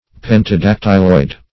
Search Result for " pentadactyloid" : The Collaborative International Dictionary of English v.0.48: Pentadactyloid \Pen`ta*dac"tyl*oid\, a. [Pentadactyl + -oid.]